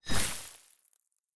Free Frost Mage - SFX
forst_nova_short_08.wav